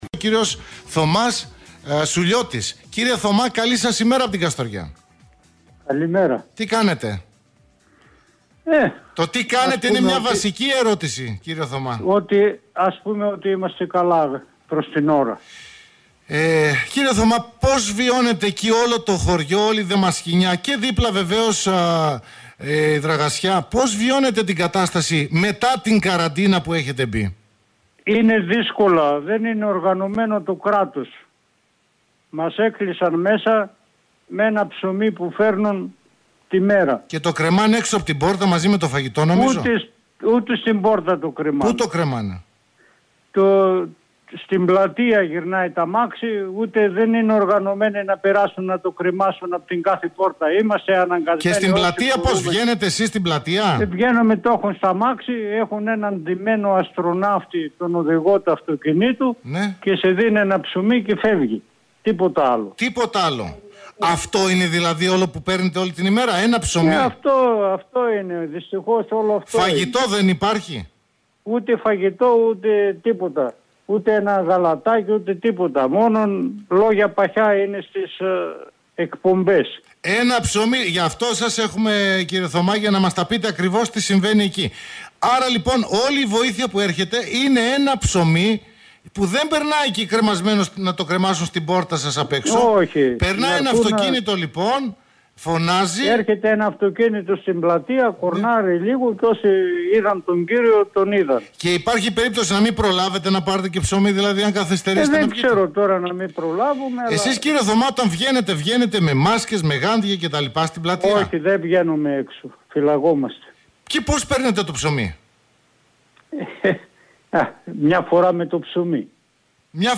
μίλησε κάτοικος του χωριού Δαμασκηνιά και εξήγησε το πώς βιώνουν οι κάτοικοι την κατάσταση μετά την καραντίνα.
katoikos_damaskinias.mp3